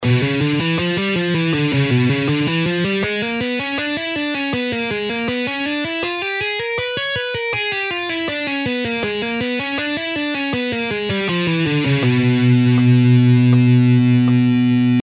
Diminished Scale:
Half Speed:
2.-Legato-Exercise-In-Diminished-Scale-Half-Speed.mp3